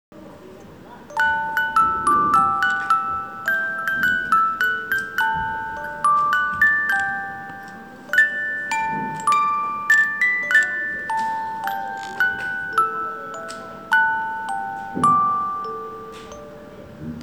長田小校歌オルゴール.WAV　クリックするとオルゴールの音が流れます。